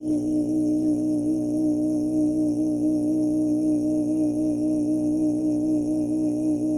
Monk Voice Low Monotone Chat